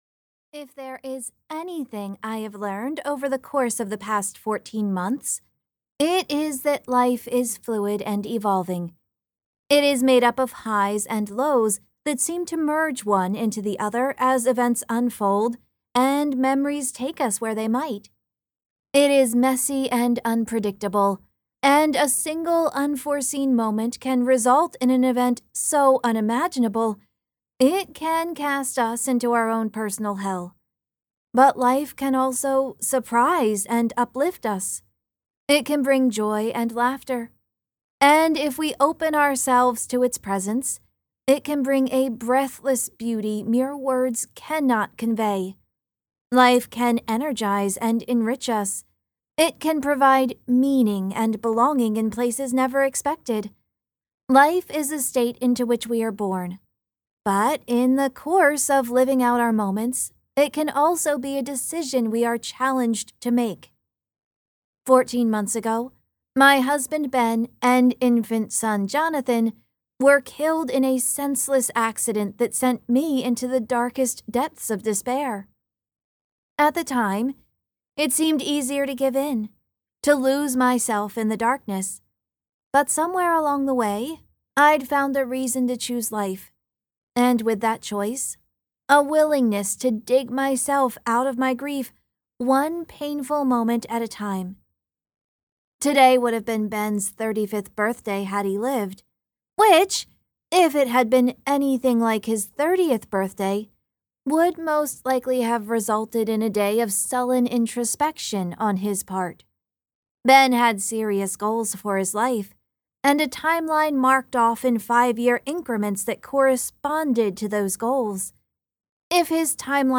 Book 2 Retail Audio Sample Letters in the Library The Inn at Holiday Bay.mp3